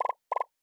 Knock Notification 1.wav